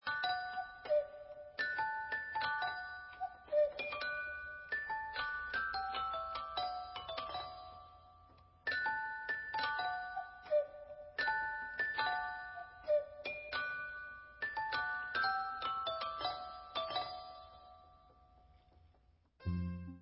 Hraci strojek z pohybliveho Betlema